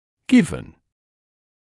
[gɪvn][gɪvn]с учётом; данность, факт; данный, установленный; 3-я форма от to give